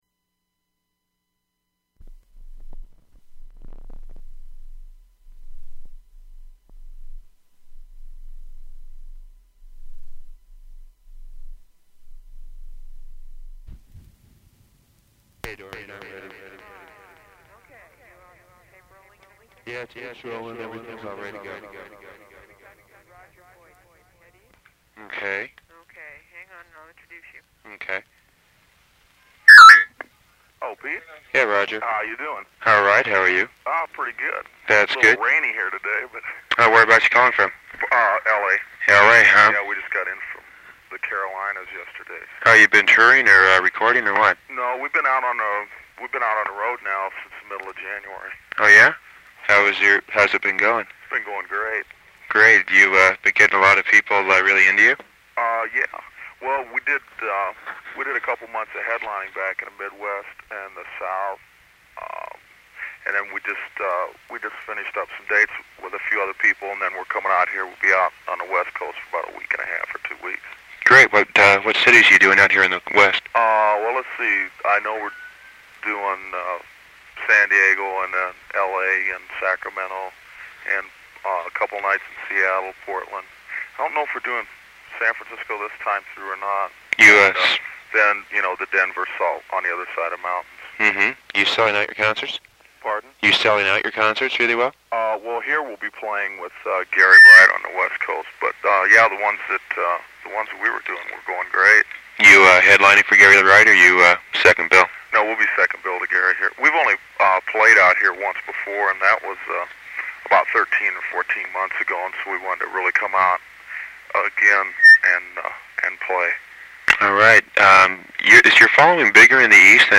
Interview
Open reel audiotape